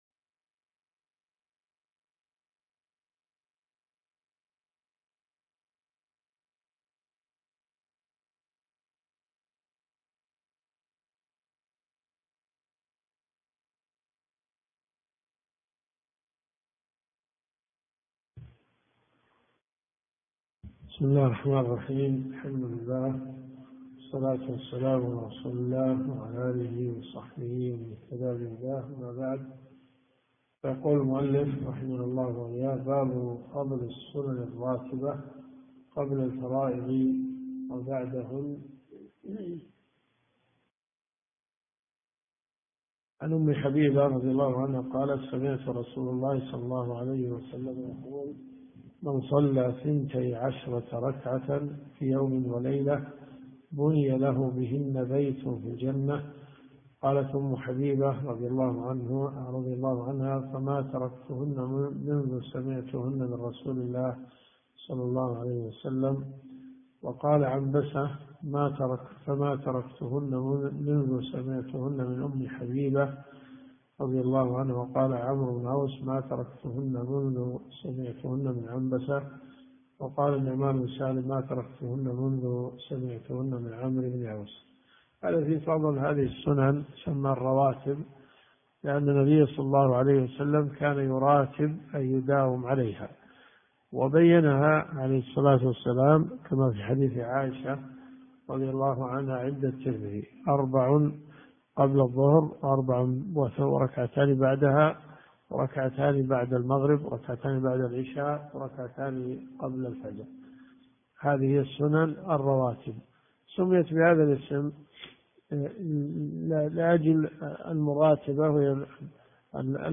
الكتب المسموعة